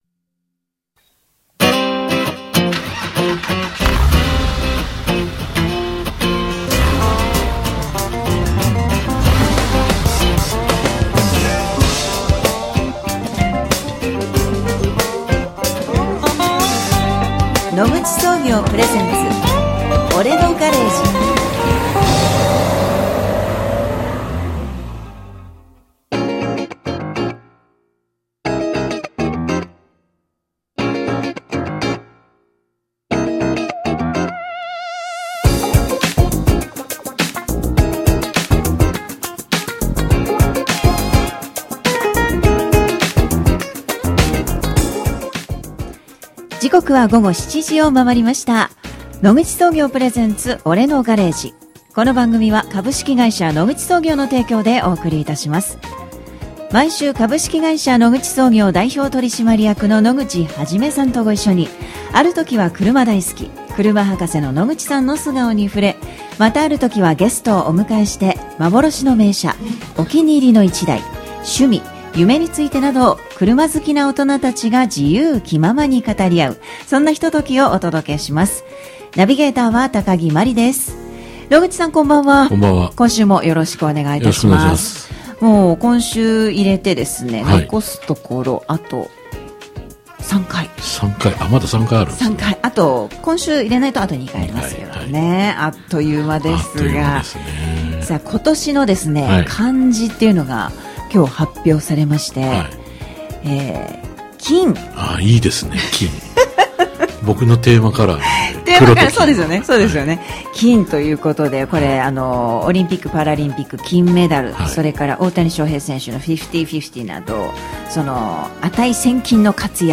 金沢シーサイドFM「俺のガレージ」に12月12日ゲスト生出演！
毎週木曜日19:00〜20:00 生放送